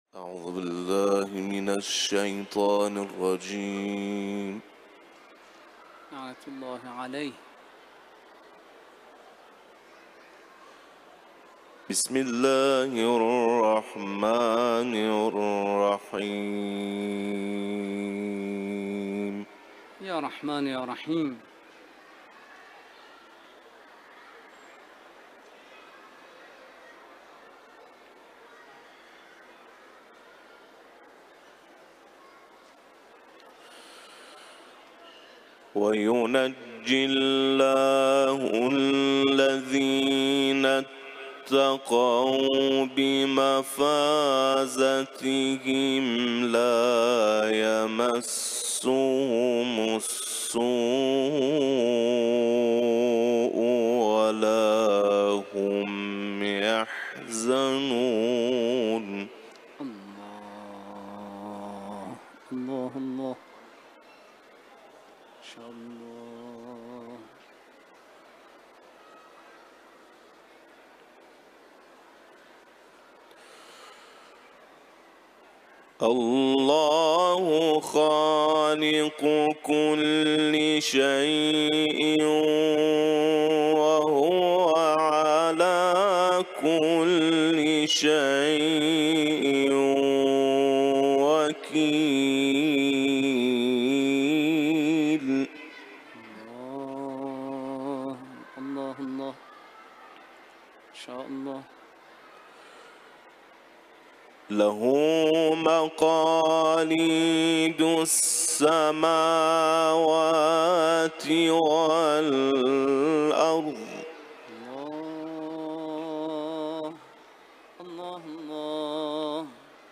সুললিত কণ্ঠে
আন্তর্জাতিক ক্বারী
এই তিলাওয়াতটি তিনি মাশহাদে ইমাম রেজা (আ.)এর পবিত্র মাযারে পরিবেশন করেছেন।